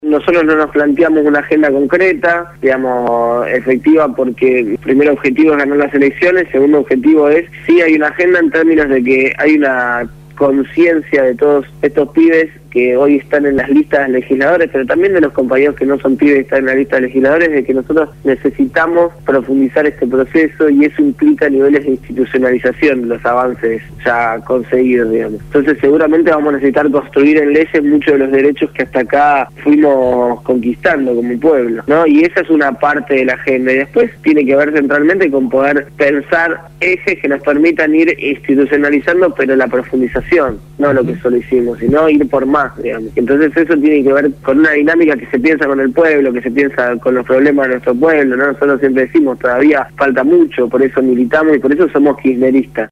candidato a diputado nacional por el Frente para la Victoria, fue entrevistado